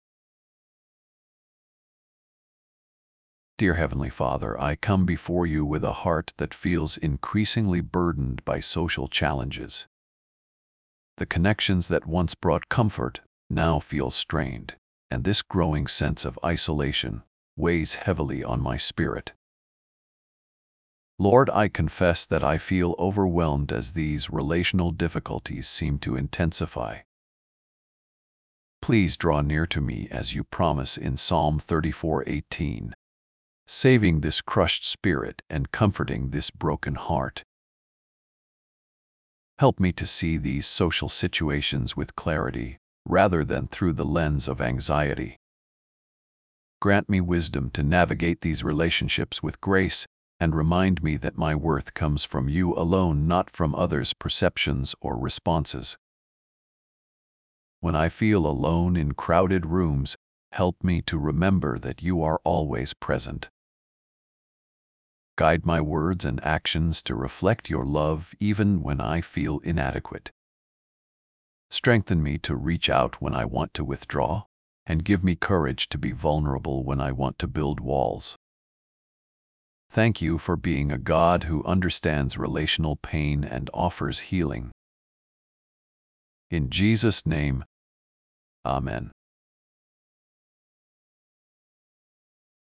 1196 -1 Prayer Prayer mood:overwhelmed